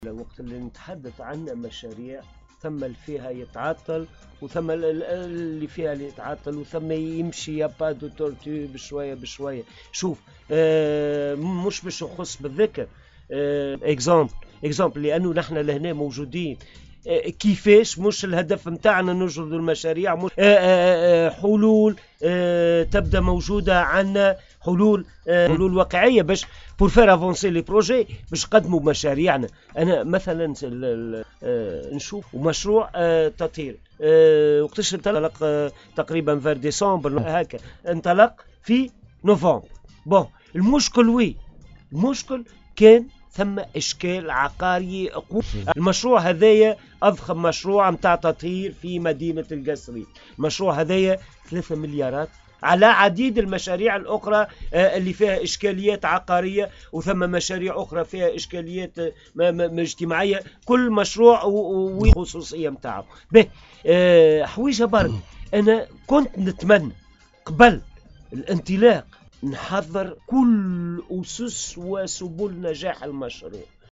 أكد المستشار البلدي محمد الرحموني اليوم 25 ماي 2019 في برنامج حديث قصارنية بإذاعة السيليوم أف أم وبخصوص التطرق إلى موضوع المشاريع المعطلة  في ولاية القصرين أن الحديث الأهم يبقى في إيجاد الحلول الفعلية والواقعية لهذه المشاريع المعطلة منذ سنوات والتي أصبحت عائقا أمام التنمية في الجهة .
المستشار البلدي محمد الرحموني